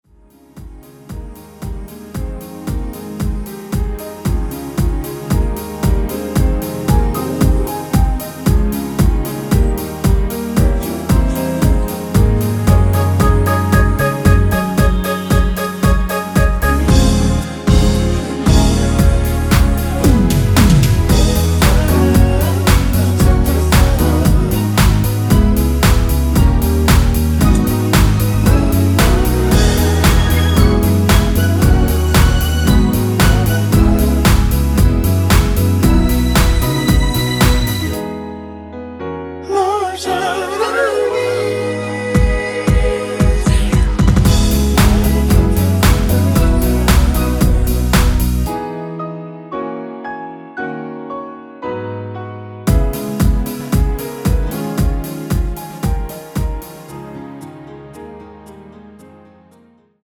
원키에서(-2)내린 코러스 포함된 MR입니다.
Ab
앞부분30초, 뒷부분30초씩 편집해서 올려 드리고 있습니다.